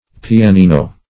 Search Result for " pianino" : The Collaborative International Dictionary of English v.0.48: Pianino \Pi`a*ni"no\, n. [It., dim. of piano, adj.